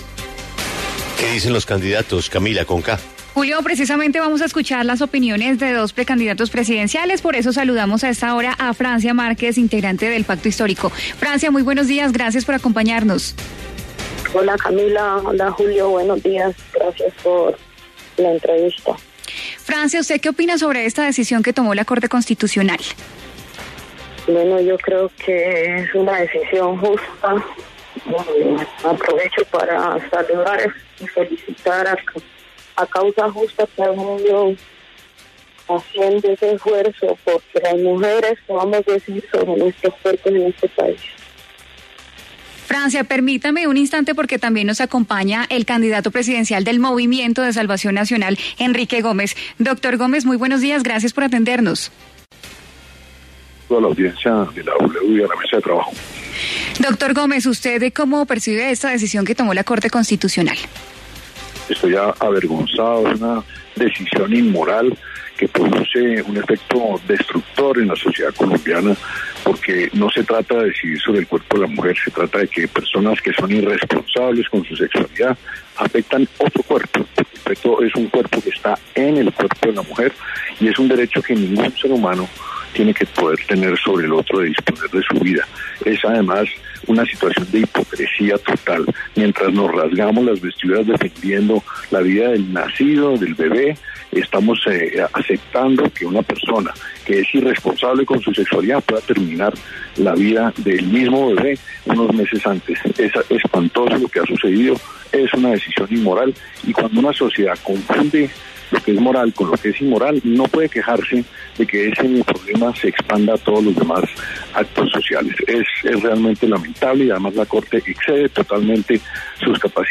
Francia Márquez y Enrique Gómez debatieron en La W sobre la decisión de la Corte Constitucional.